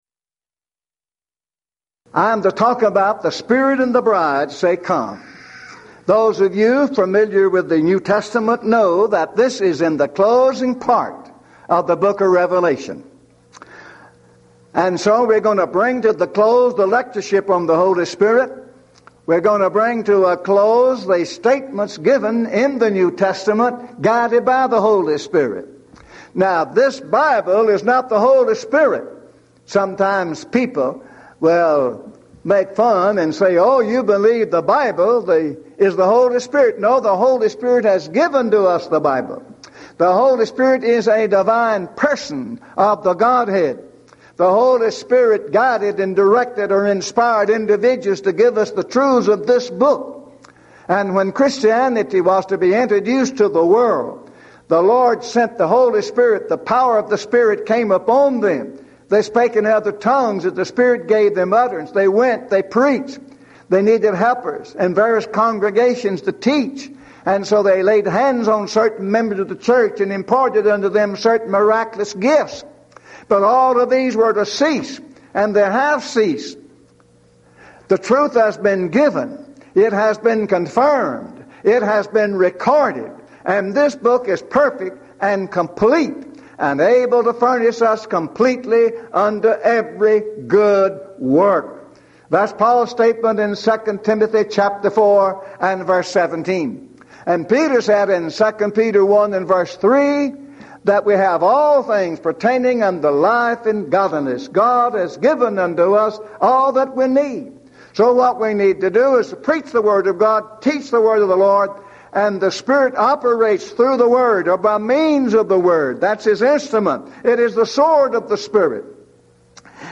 Event: 1997 Mid-West Lectures Theme/Title: God The Holy Spirit
this lecture